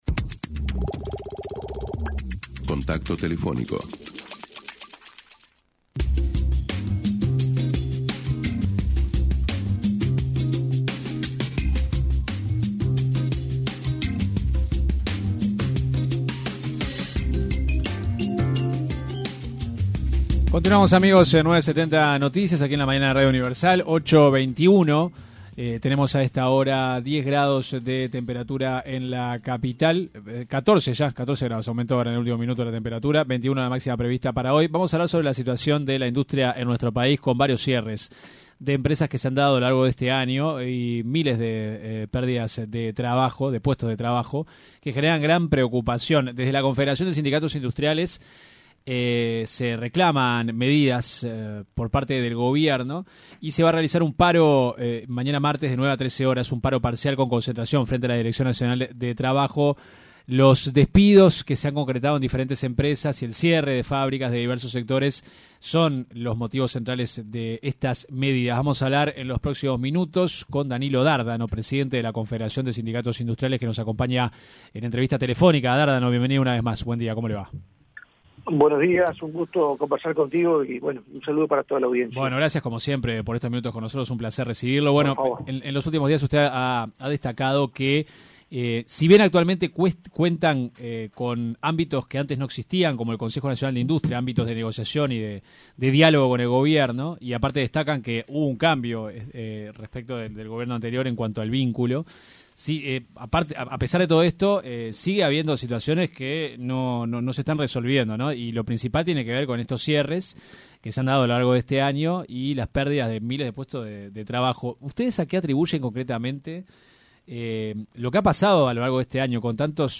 entrevista con 970 Noticias